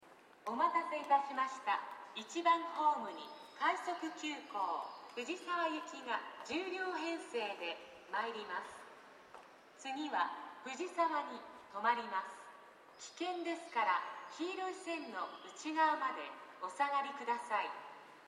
この駅では接近放送が設置されています。
１番ホームOE：小田急江ノ島線
接近放送快速急行　藤沢行き接近放送です。